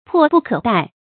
迫不可待 注音： ㄆㄛˋ ㄅㄨˋ ㄎㄜˇ ㄉㄞˋ 讀音讀法： 意思解釋： 見「迫不及待」。